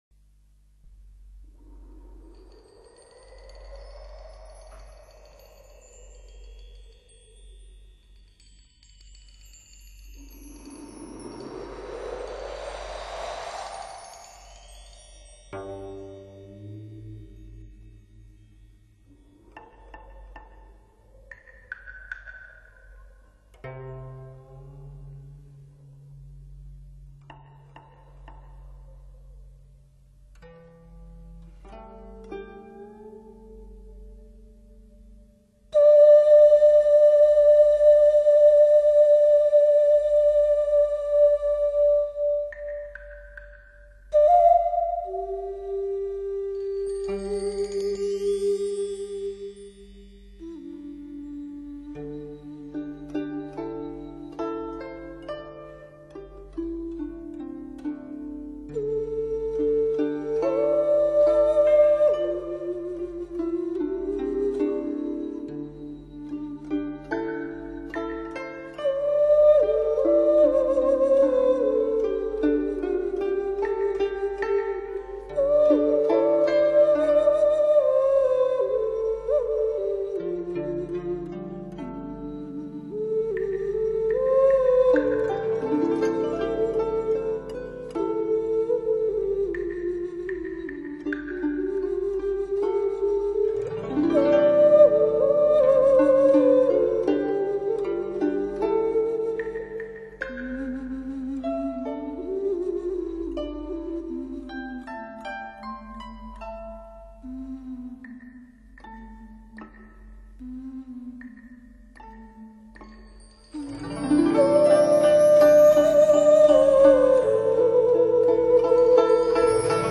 音色明显带有商周时代所特有的精神气质：古朴、浑厚、低沉、沧桑、神秘、哀婉。。。